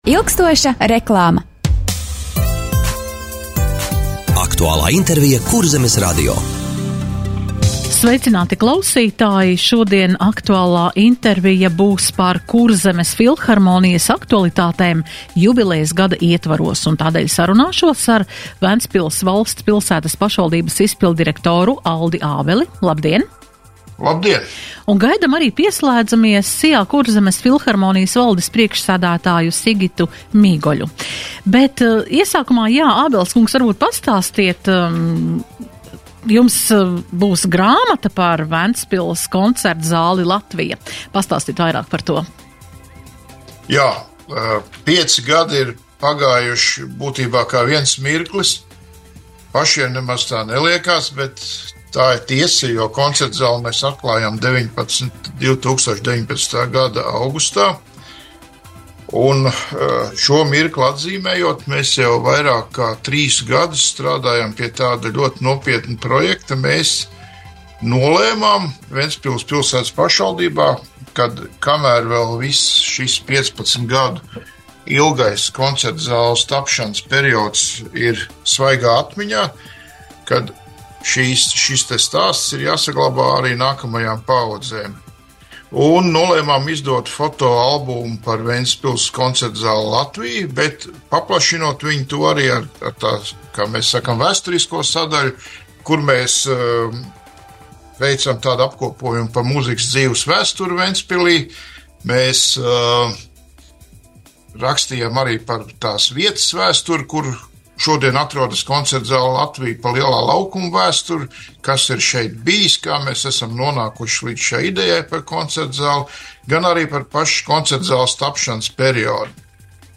Radio saruna Kurzemes filharmonijas aktualitātes jubilejas gada ietvaros - Ventspils